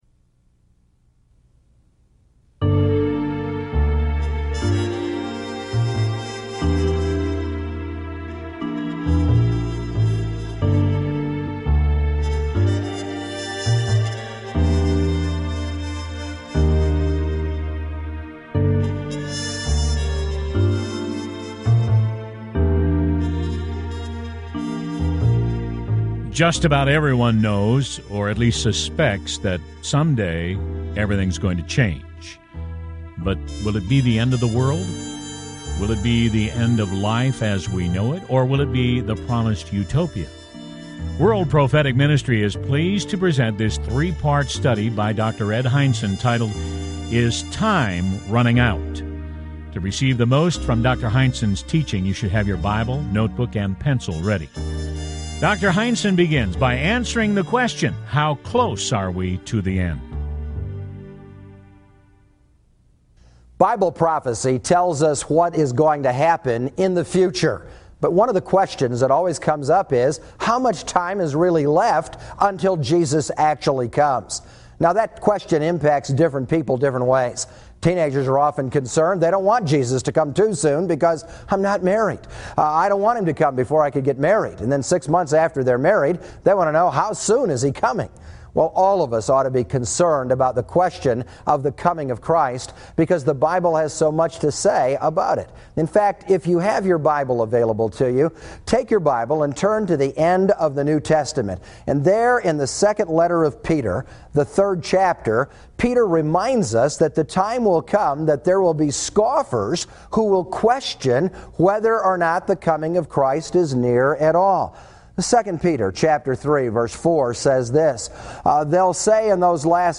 Talk Show Episode, Audio Podcast, The King Is Coming and Time Is Running Out on , show guests , about Time Is Running Out, categorized as History,News,Christianity,Society and Culture,Theory & Conspiracy